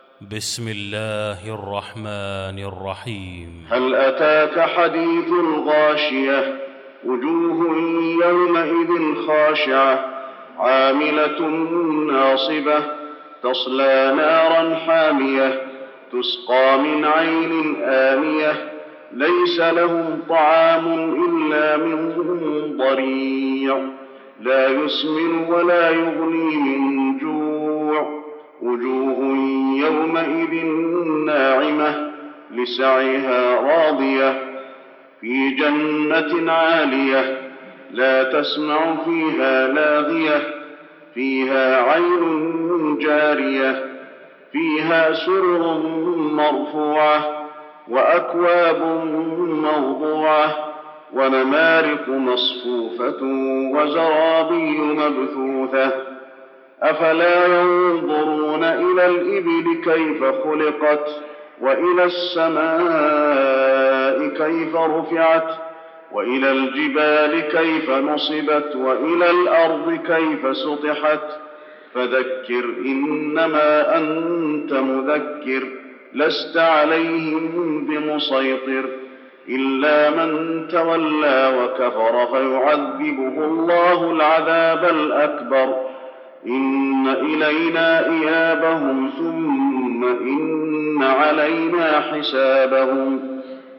المكان: المسجد النبوي الغاشية